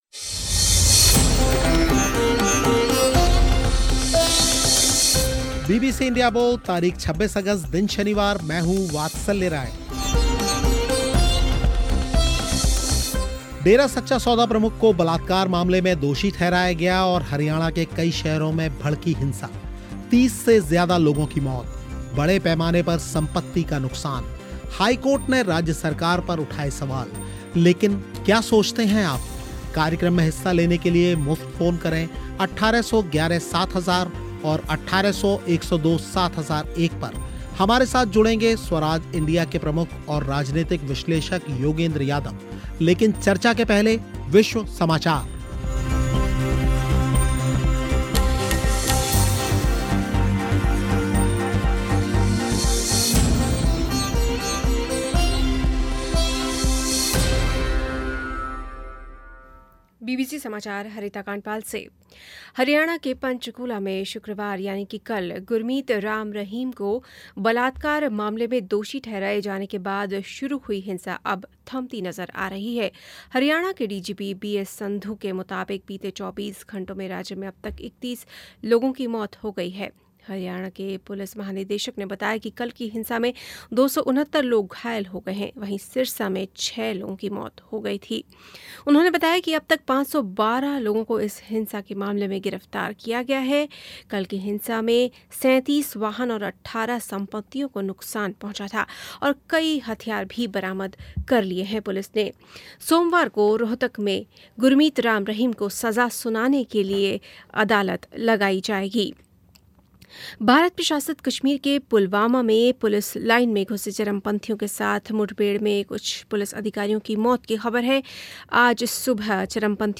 डेरा सच्चा सौदा प्रमुख को बलात्कार मामले में दोषी ठहराया गया और हरियाणा के कई शहरों में भड़की हिंसा 30 से ज्यादा लोगों की मौत, बड़े पैमाने पर संपत्ति का नुकसान हाईकोर्ट ने राज्य सरकार पर उठाए सवाल इस घटनाक्रम के बाद उठे सवालों पर हुई चर्चा में हिस्सा लिया स्वराज इंडिया के प्रमुख और राजनीतिक विश्लेषक योगेंद्र यादव ने